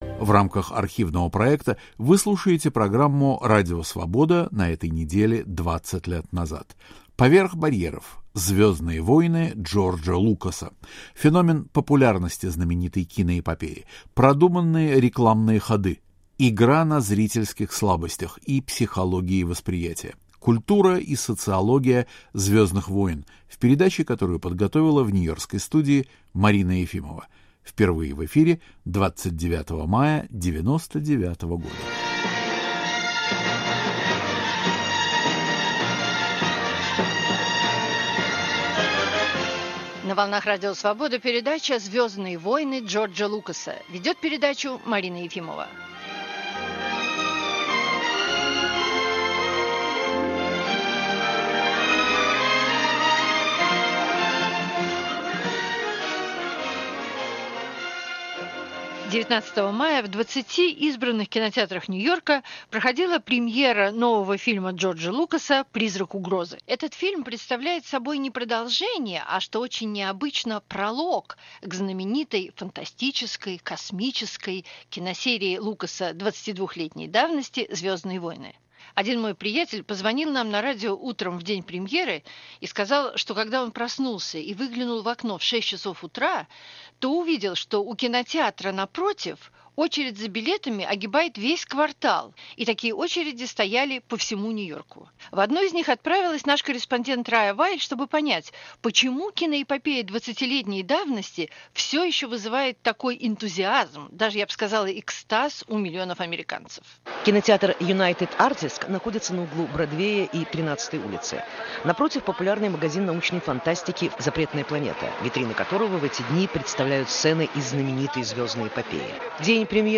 американские фанаты "Звездных войн"